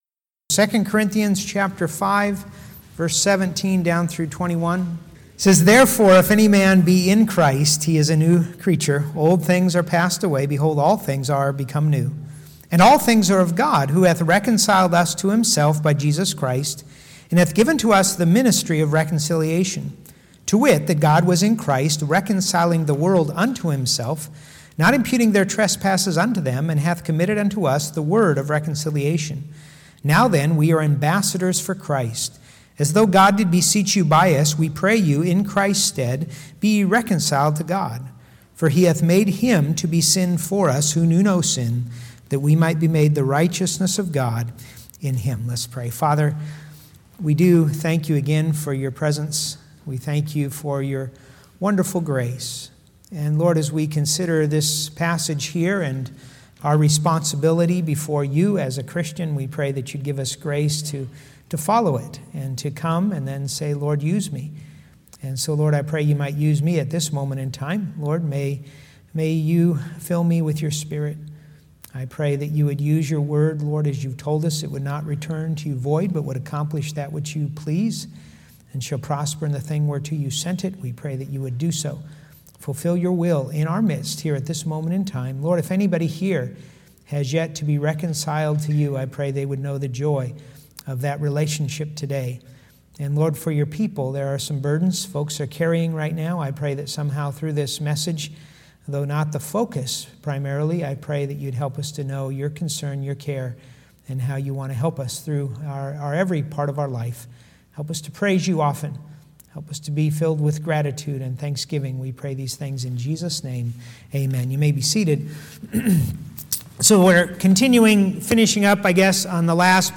Sunday AM | II Corinthians 5:17-21